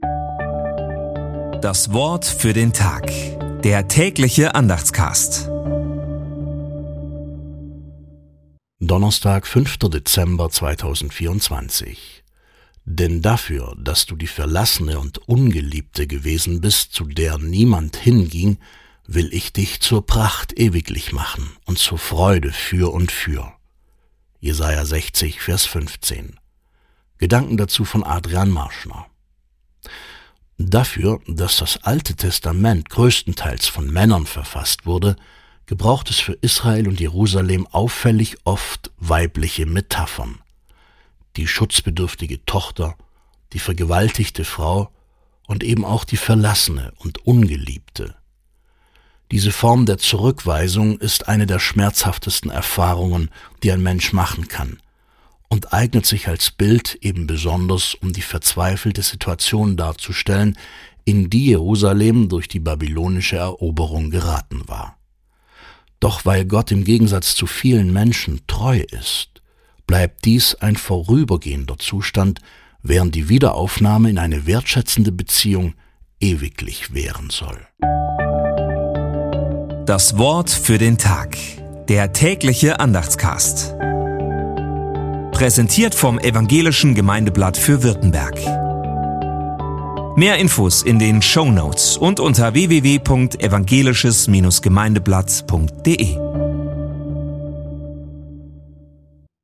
Sprecher